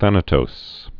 (thănə-tōs)